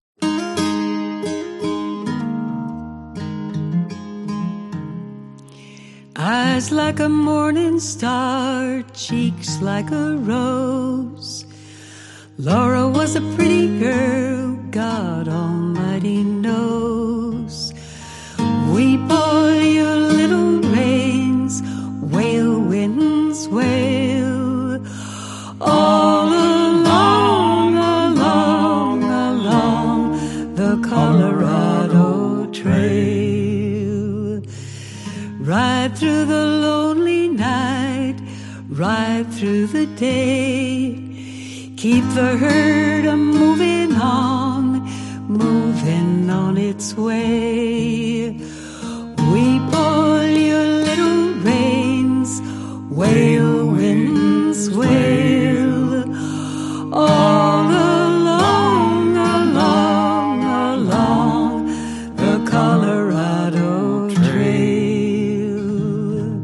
This is a love song from the late 1800s from Deluth, MN.